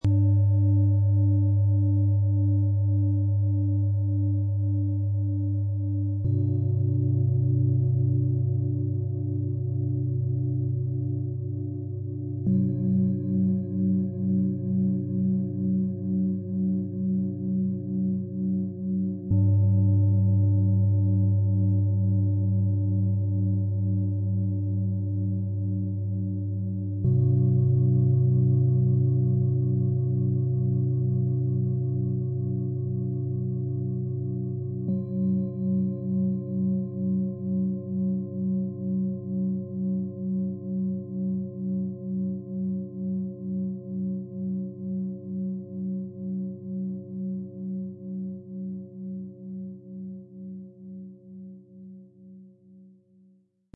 Erden, lösen, entspannen - Klassisches Klangmassage Set mit 3 glänzenden Klangschalen, Ø 17,3 - 25 cm, 3,15 kg
Drei kräftige und glänzende Klangschalen mit intensiver Schwingung und tiefer Vibration bringen Körper und Geist zur Ruhe.
Diese große Klangschale schenkt tiefe, tragende Klänge, die lange im Raum stehen.
Warmer, voller Klang breitet sich in Brust und Rücken aus.
Ihr heller Ton klärt den Geist, ohne scharf zu wirken.
Im Sound-Player - Jetzt reinhören können Sie den Original-Ton genau dieser Schalen aus dem Set anhören - so, wie sie wirklich klingen.
MaterialBronze